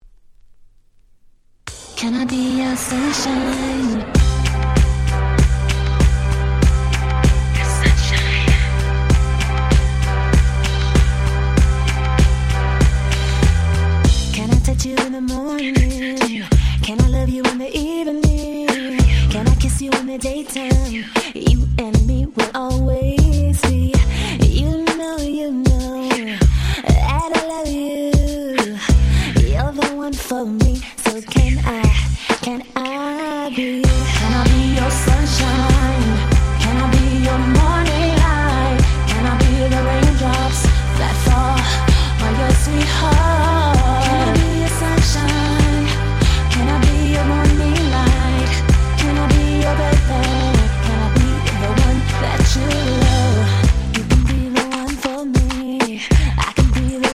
03' Nice R&B !!